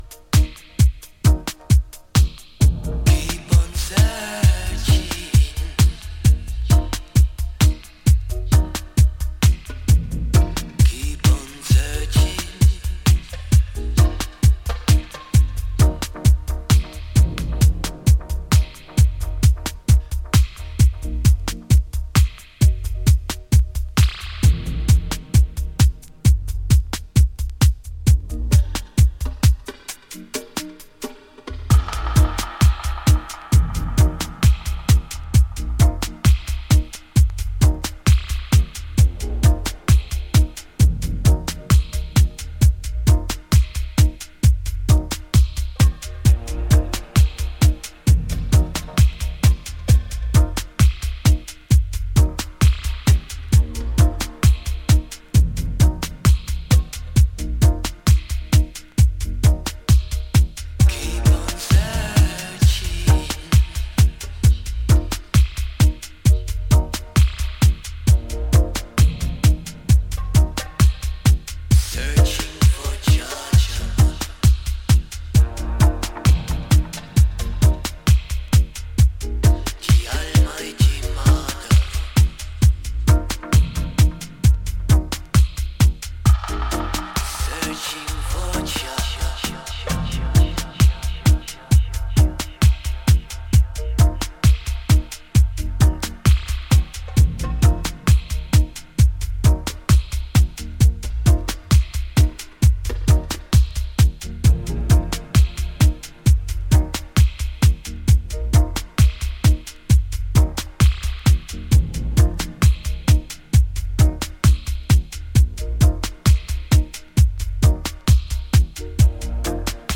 より深いグルーヴで引き込む